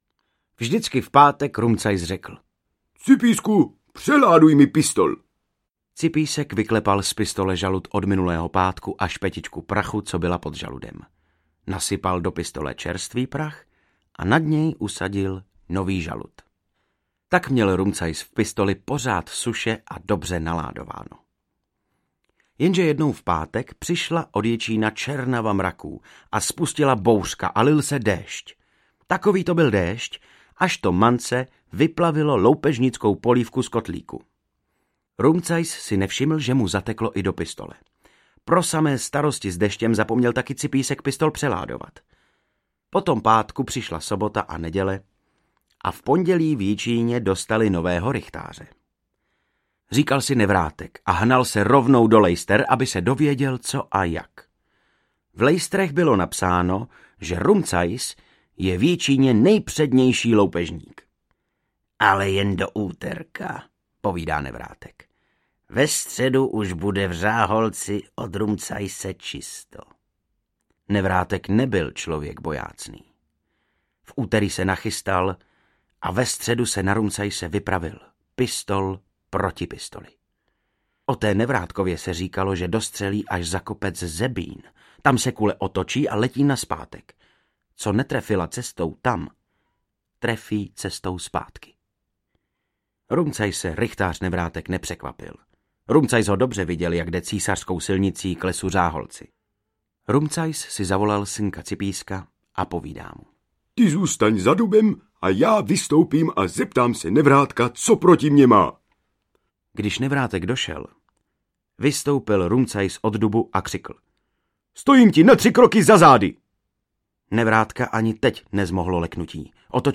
Rumcajsova loupežnická knížka & Vánoce u Rumcajsů audiokniha
Padesát let Večerníčků o loupežníku Rumcajsovi oslavíme nově objevenými pohádkami načtenými vynikajícím Vojtěchem Kotkem
Ukázka z knihy